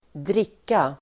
Uttal: [²dr'ik:a]